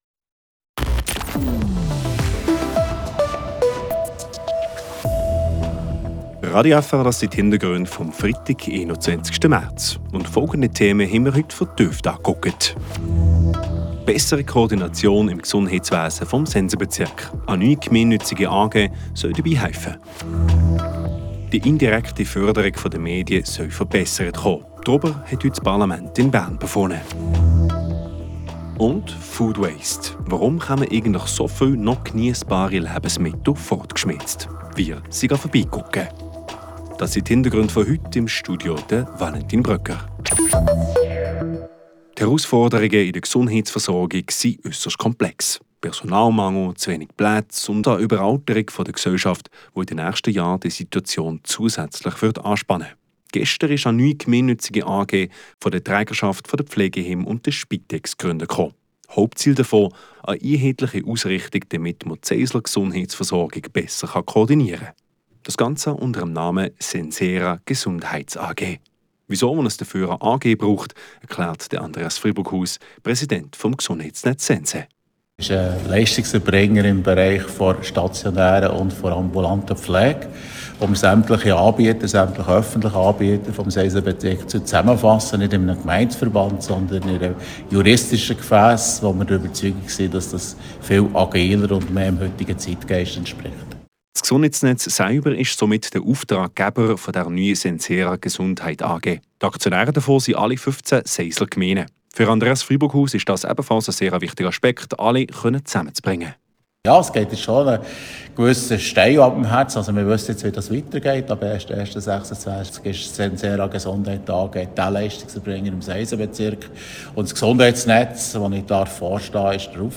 In einer Reportage fragen wir bei Grossverteilern in der Region nach den Gründen für den Food-Waste.